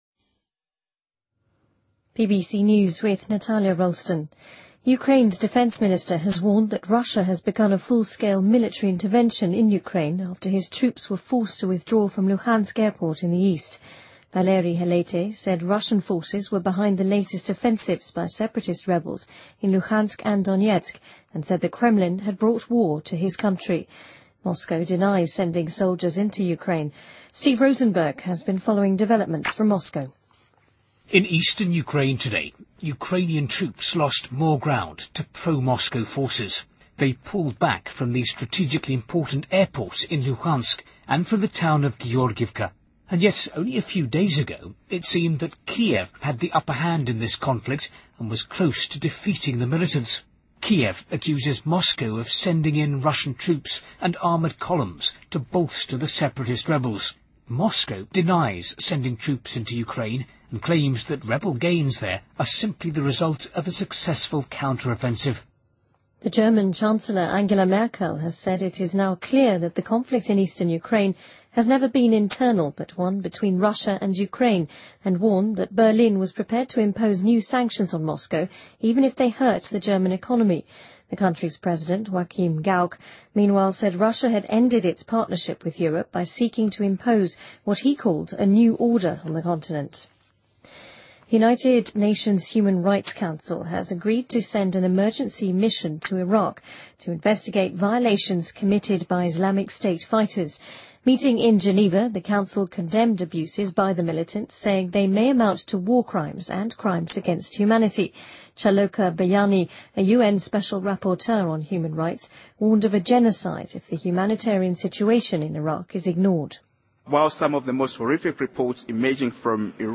BBC news,美国要求朝鲜释放3名被拘公民